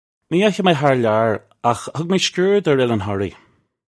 Pronunciation for how to say
Nee yahee may harr lyar akh hug may skoord urr Ill-an Horree. (U)
This is an approximate phonetic pronunciation of the phrase.